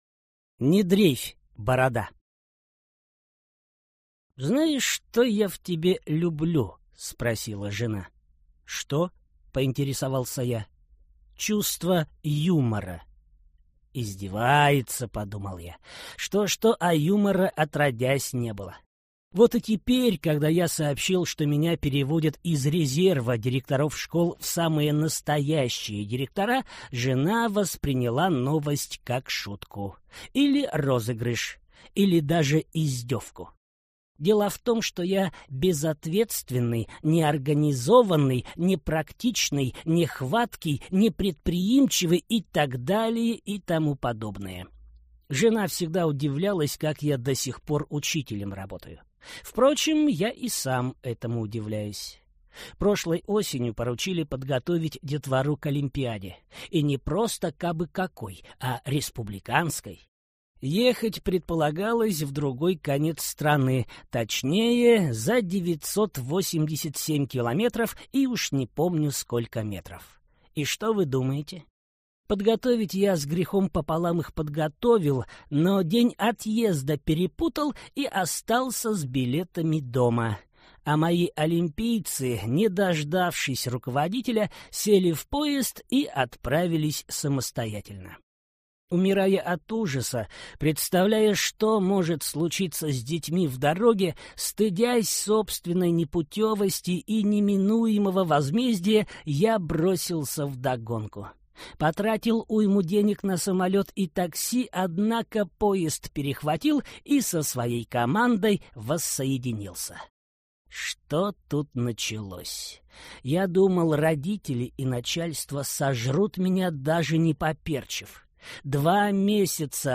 Аудиокнига Не дрейфь, борода!